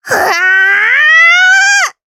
Taily-Vox_Casting4_jp.wav